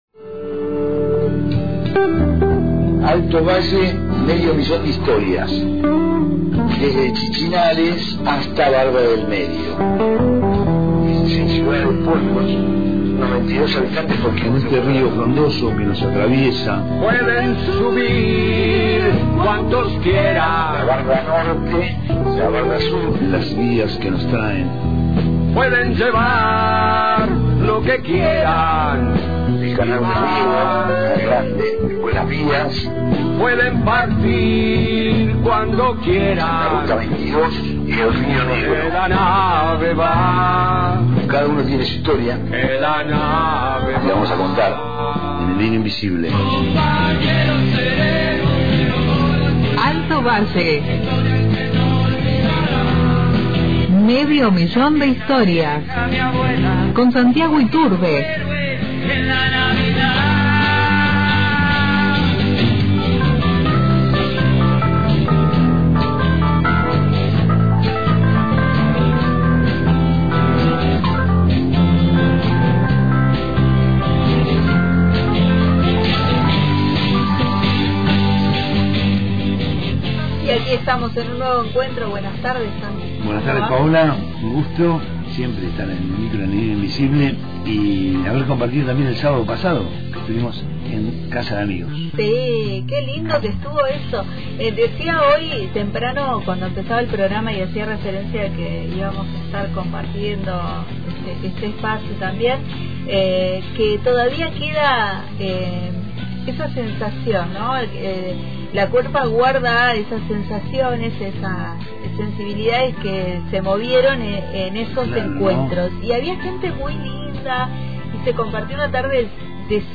El evento fue un crisol de arte, poesía y encuentro, con títeres, esgrima antigua, esculturas, danzas y lecturas, todo transmitido en vivo por El Hilo Invisible.